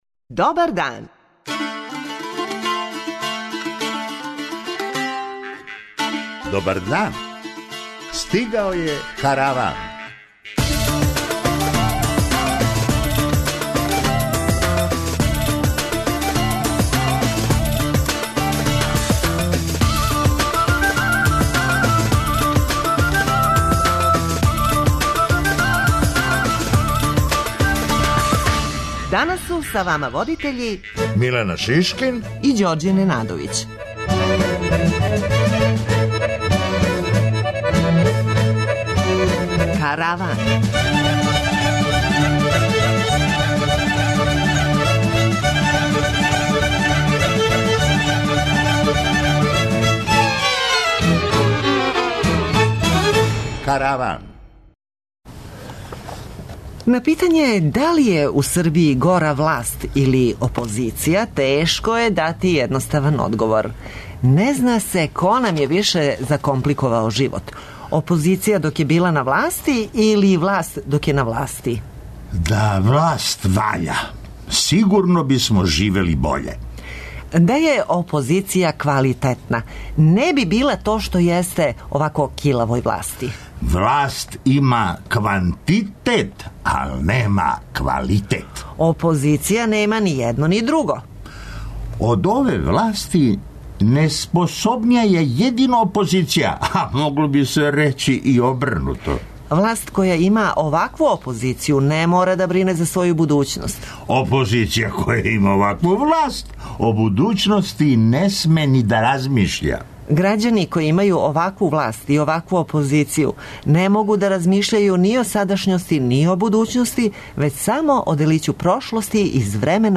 [ детаљније ] Све епизоде серијала Аудио подкаст Радио Београд 1 Подстицаји у сточарству - шта доносе нове мере Хумористичка емисија Хумористичка емисија Корак ка науци Афера Епстин "не пушта" британског премијера